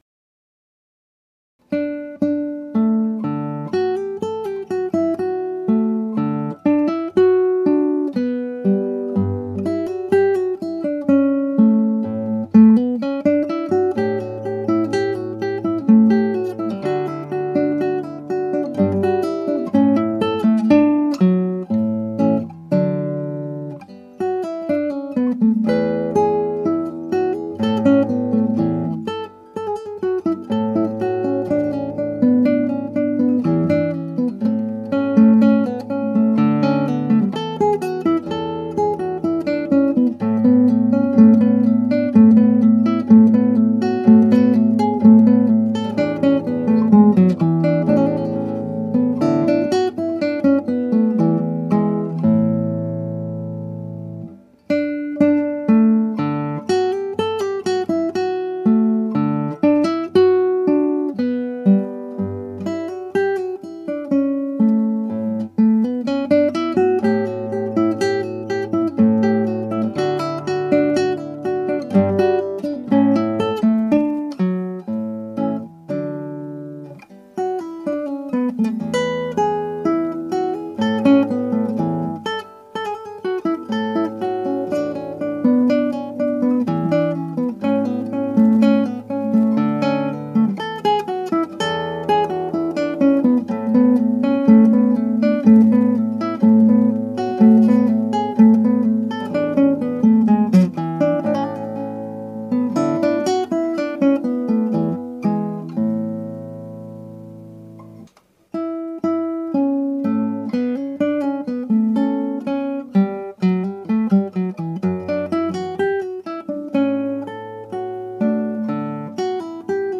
(アマチュアのクラシックギター演奏です [Guitar amatuer play] )
原調はト長調ですがギター版はニ長調です。
42小節の曲ですが次の28小節について音の変更をして弾いています。
練習室でアップしたより早くはなりましたがクーラントとしてはもう少し早いテンポで弾きたいところです。リズムもちょっと変なところがありますが一応の区切りとしてアップしました。